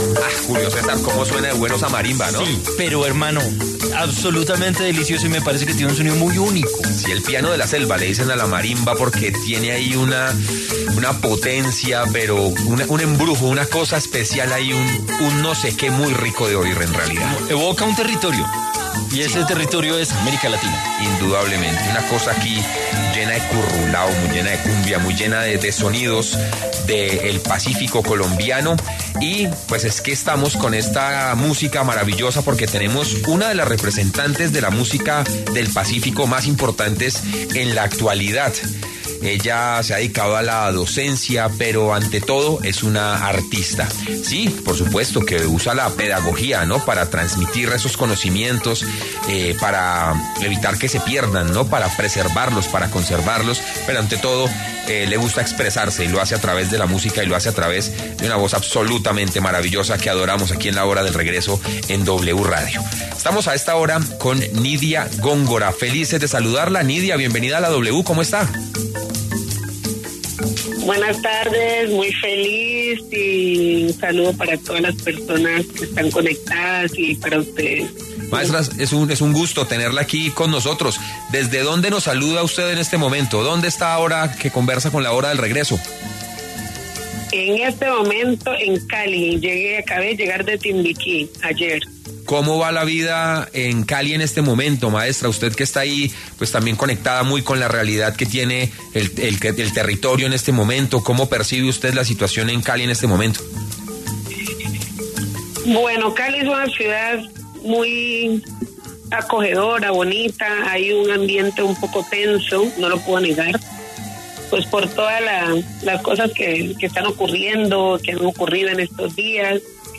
En conversación con La Hora del Regreso, Nidia Góngora habló de su trabajo para dar a conocer la cultura colombiana y del Pacífico a través de la música.